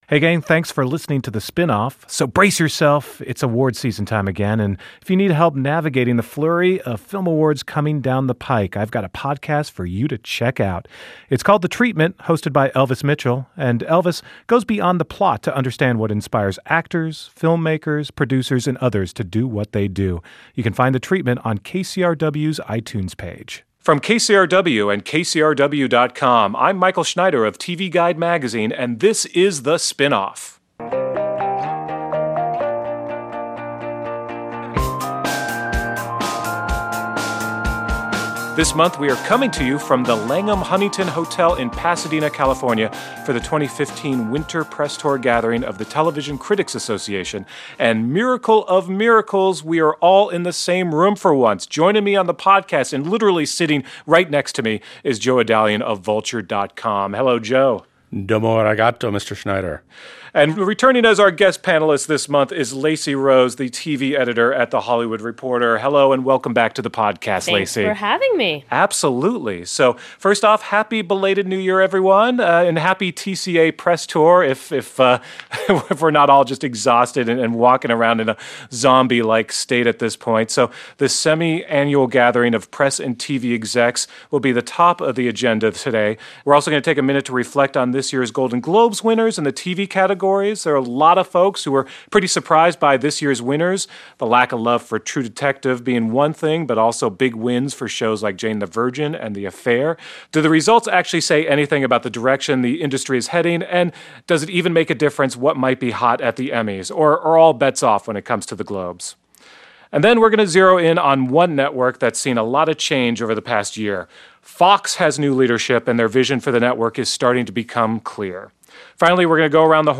We gather at the Langham Huntington Hotel in Pasadena, site of the Winter 2015 TCA gathering, to talk the latest news coming out of this semi-annual conference of TV reporters, critics and network…
Coming to you from the home of the winter TCA gathering, we take a look at big news out of the conference so far.